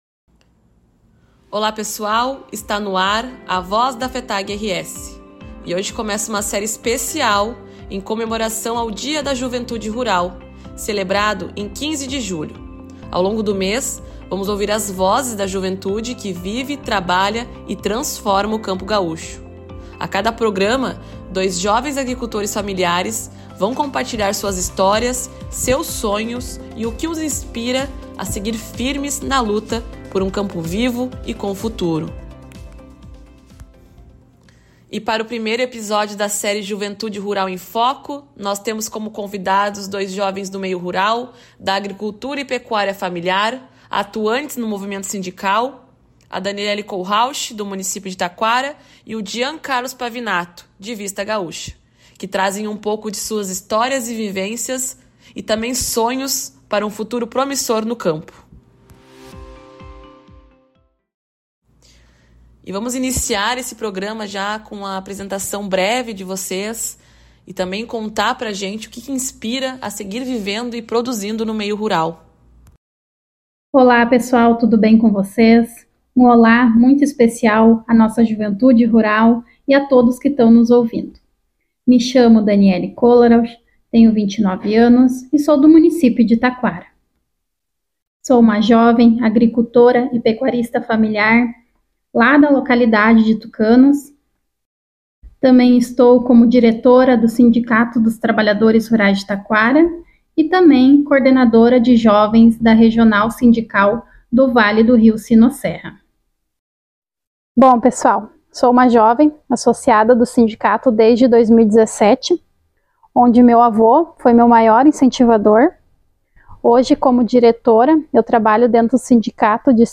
Programa de Rádio A Voz da FETAG-RS
A cada programa, dois jovens agricultores e dirigentes da juventude sindical vão compartilhar suas histórias, seus sonhos e o que os inspira a seguir firmes na luta por um campo vivo e com futuro.